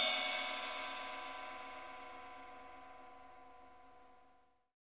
Index of /90_sSampleCDs/Roland L-CD701/CYM_Rides 1/CYM_Ride Modules
CYM ROCK 07R.wav